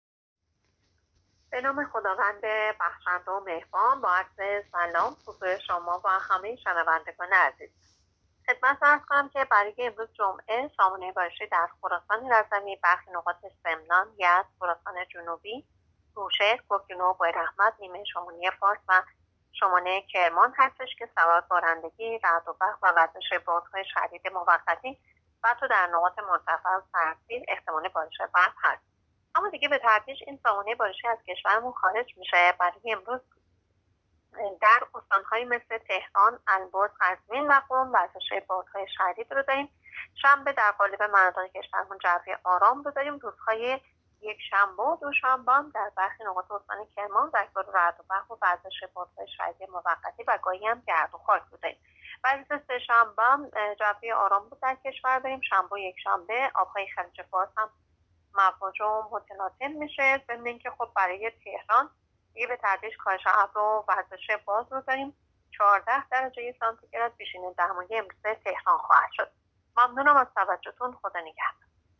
گزارش رادیو اینترنتی پایگاه خبری از آخرین وضعیت آب‌وهوای ۲۷ آبان؛